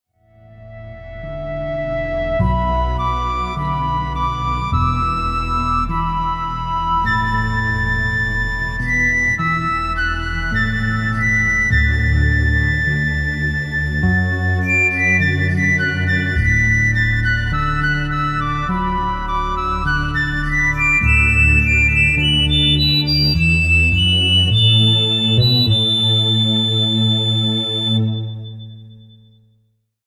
A major